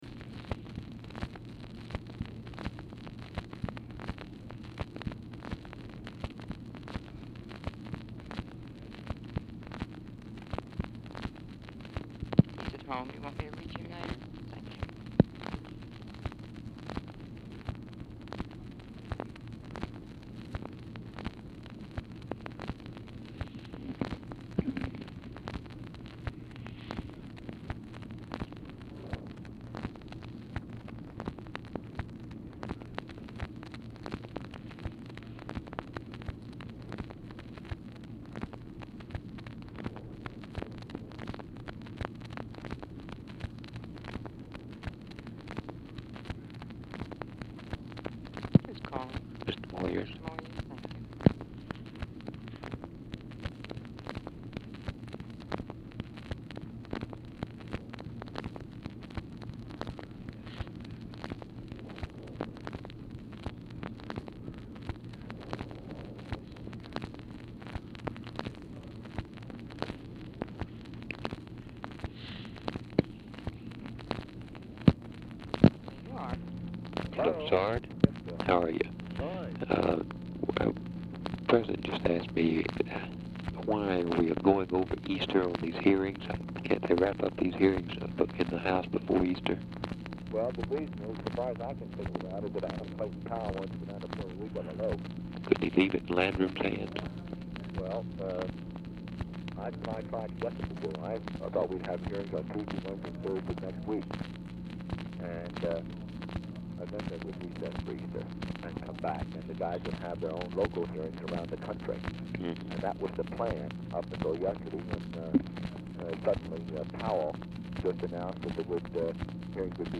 Telephone conversation # 2592, sound recording, BILL MOYERS and SARGENT SHRIVER, 3/21/1964, time unknown | Discover LBJ
Format Dictation belt
Location Of Speaker 1 Oval Office or unknown location